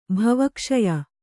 bhava kṣaya